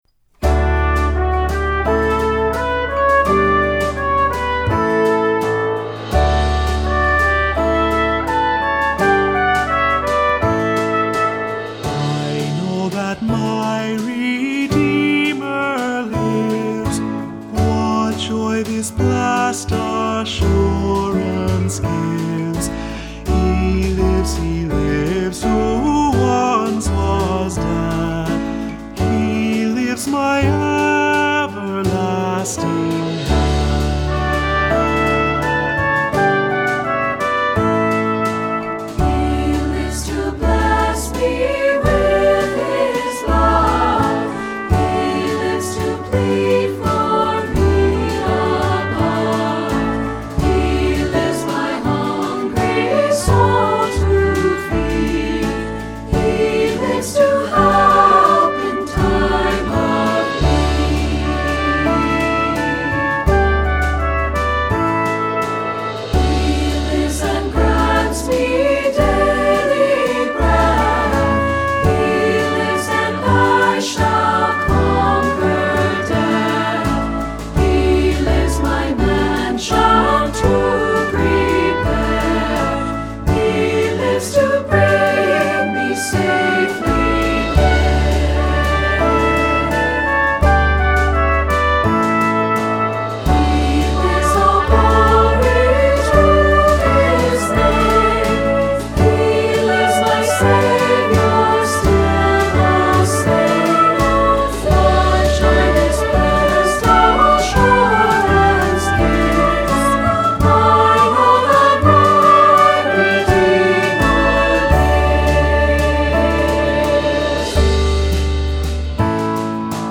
Accompaniment:      Piano
Music Category:      Christian